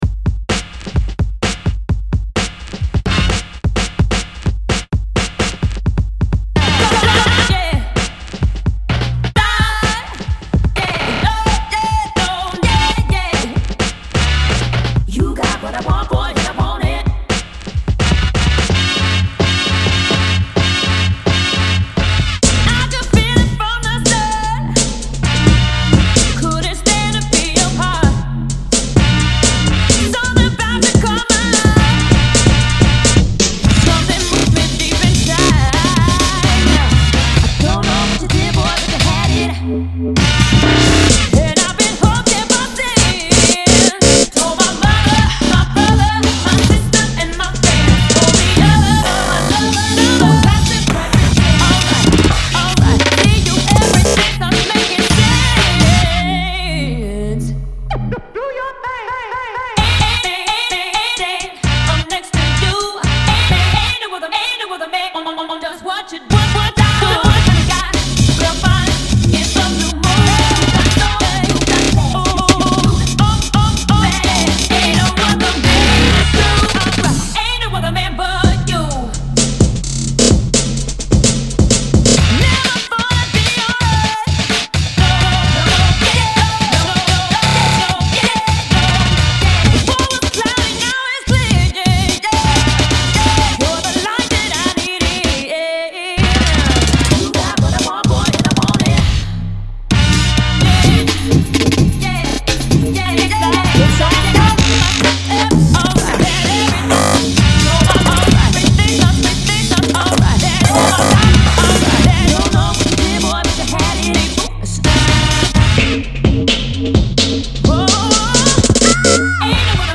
Free Tunes, Mashups / Remixes, Podcast